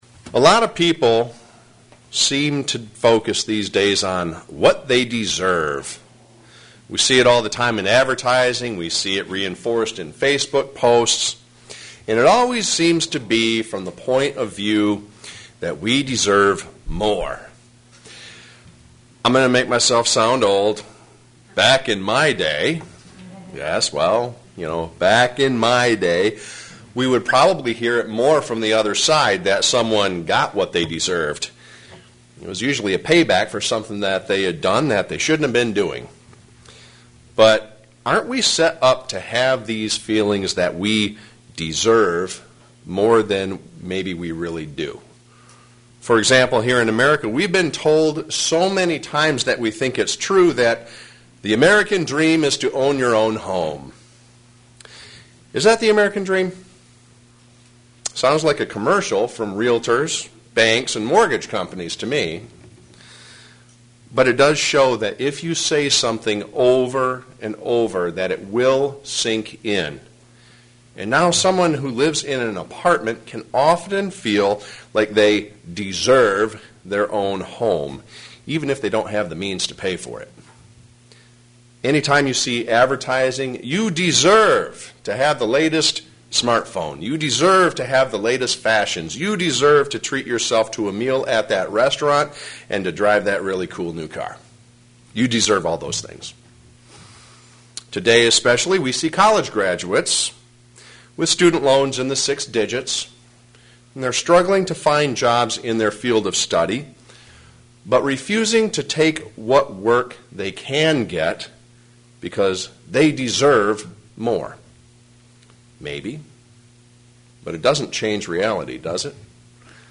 Given in Flint, MI
Today, we will examine how this affects our calling to be leaders in God's kingdom. sermon Studying the bible?